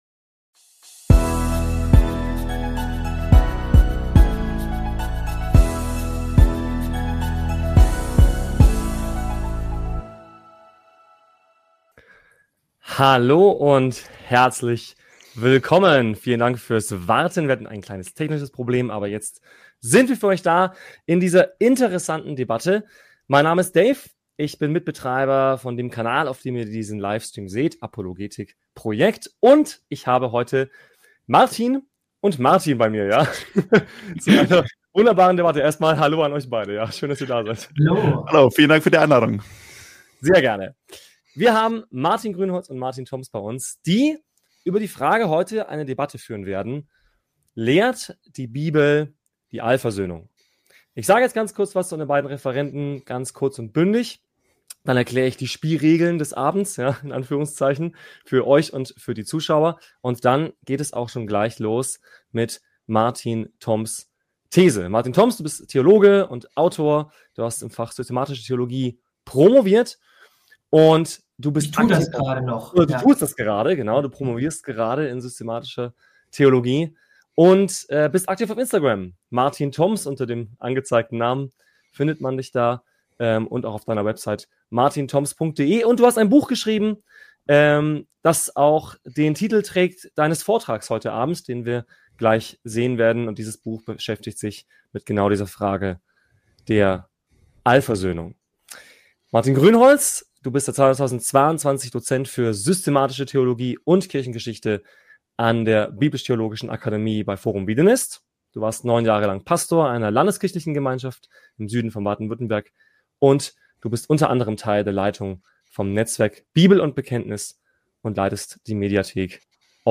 Debatte: Lehrt die Bibel die Allversöhnung?